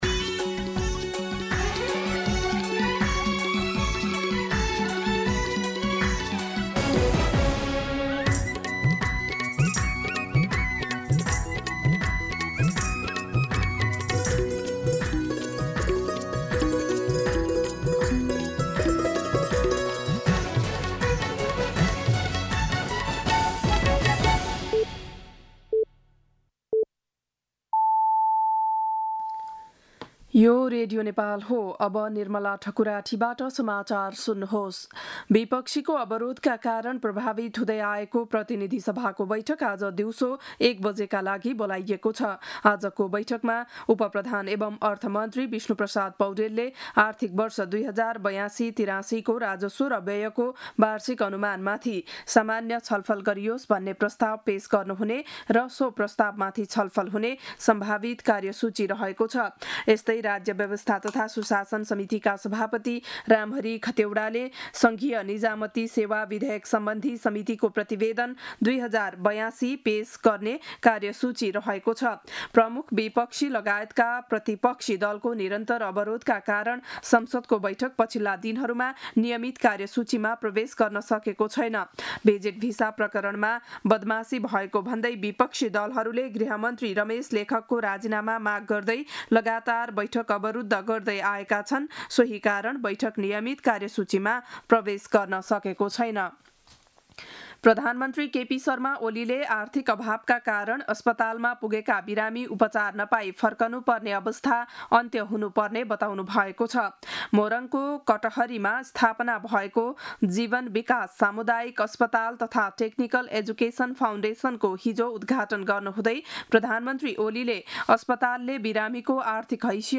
बिहान ११ बजेको नेपाली समाचार : २५ जेठ , २०८२
11am-News-25.mp3